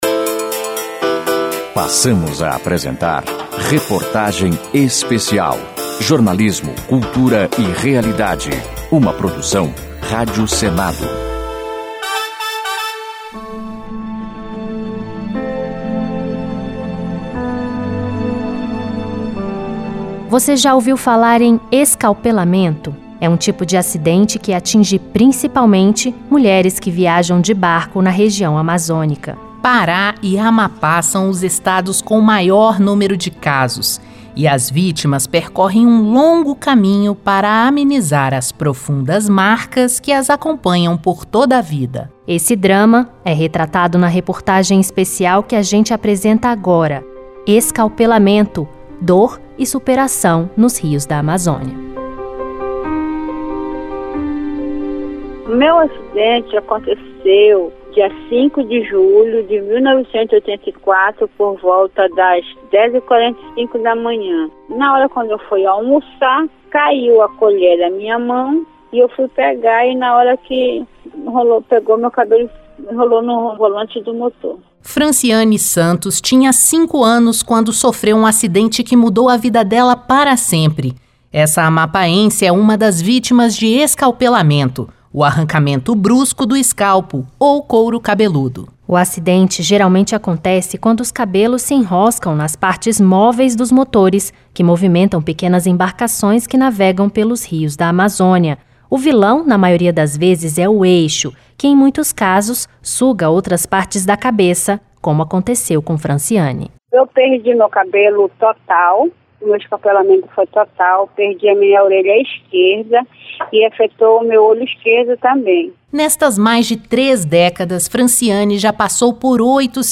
Cinco relatos dramáticos.